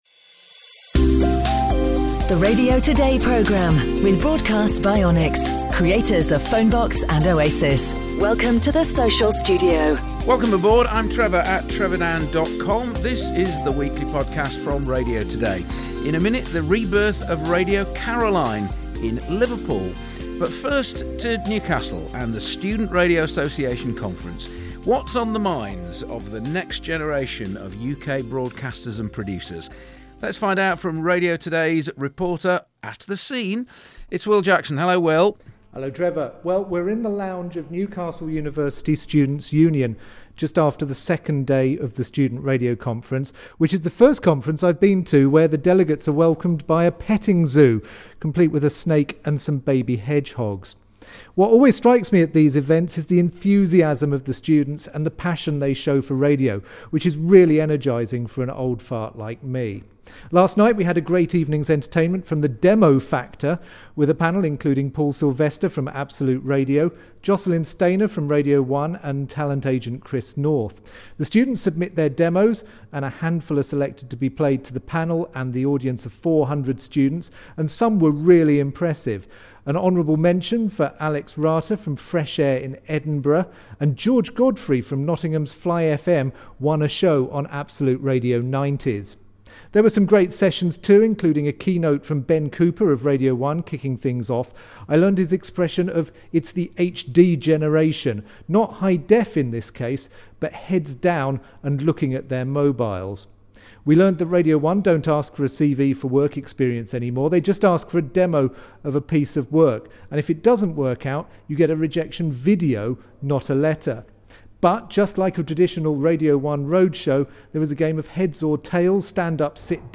report from the Student Radio Conference